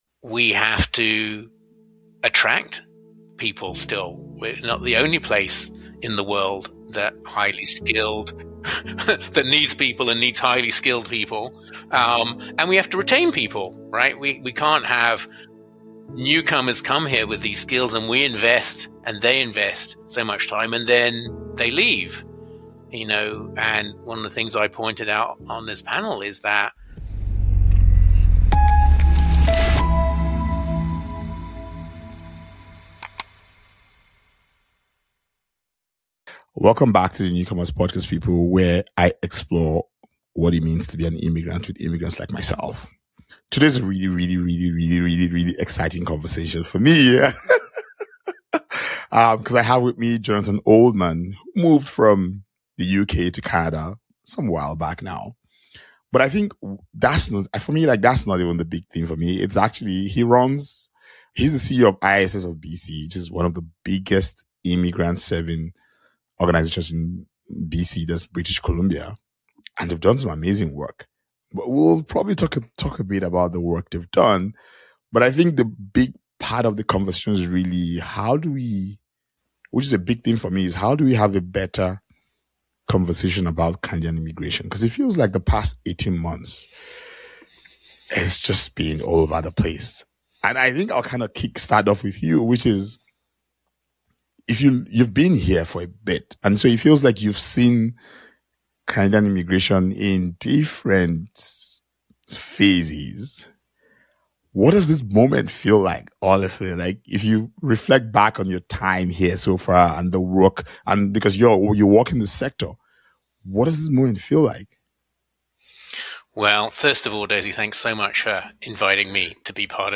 Note: This conversation was recorded on February 13, 2025.